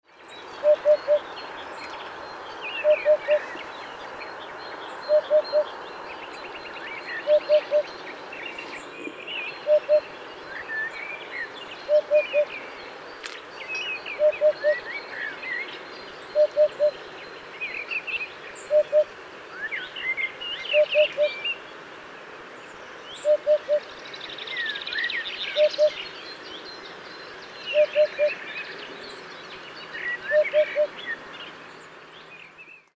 Engoulevent, Martinet,Huppe, Martin pêcheur, Guêpier – Chants d'oiseaux en Bourgogne
Huppe fasciée Upupa epops
Huppe_fasciee.mp3